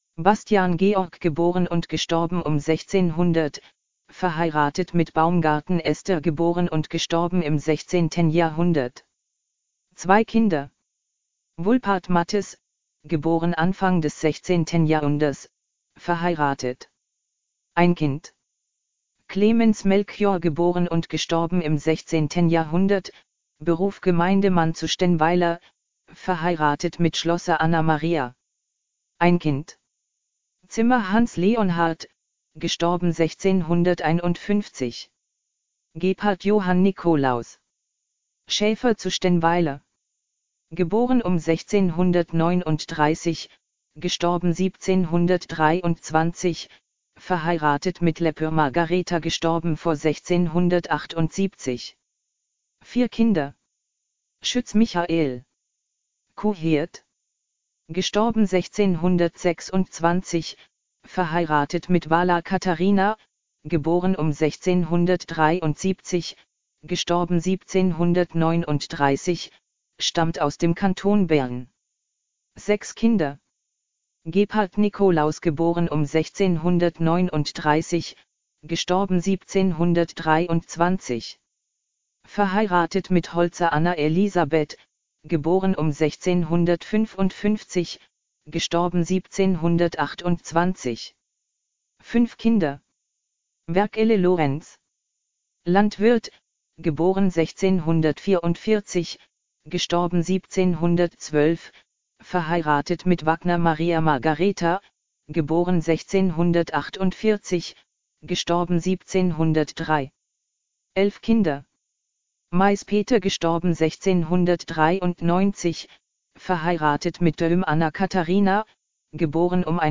Aus den mit den Kabeln verbundenen Lautsprecher sind aktuelle Umgebungsgeräusche der Linde zu hören. Sowie die Lebensdaten der Bevölkerung von Stennweiler ab 1620.
8 Kanal Klang-Installation